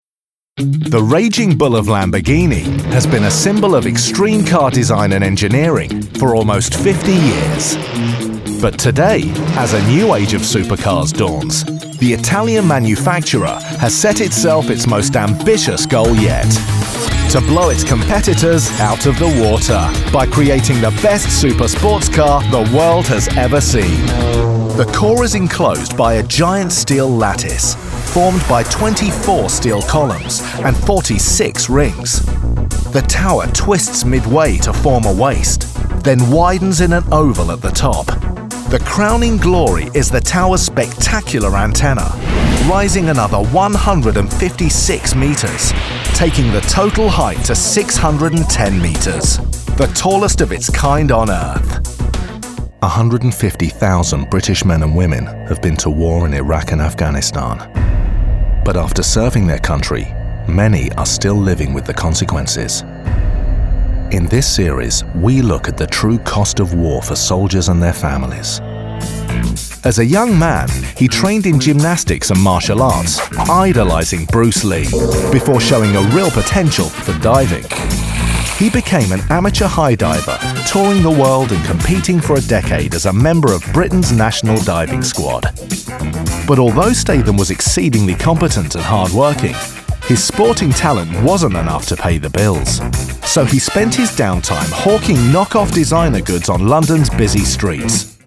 Current, relatable and versatile London voice. Characters, accents and impressions a specialty.
britisch
Sprechprobe: Industrie (Muttersprache):